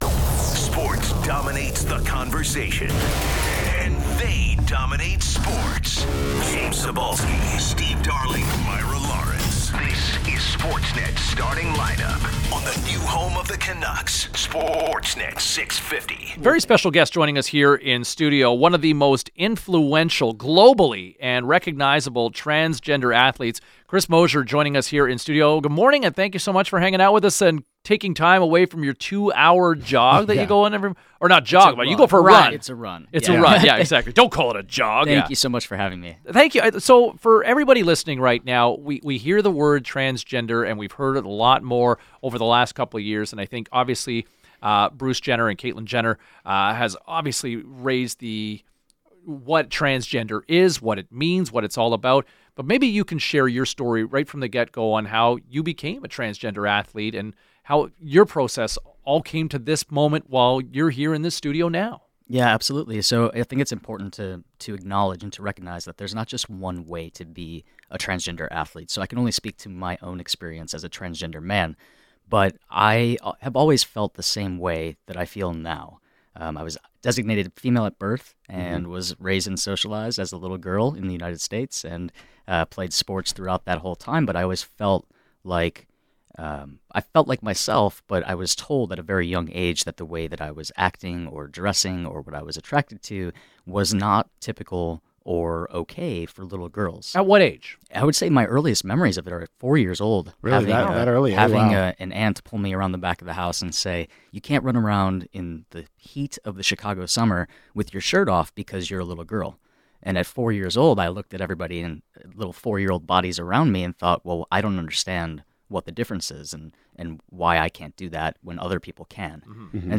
“I do have a team,” he told the hosts of the Starting Lineup on Sportsnet 650.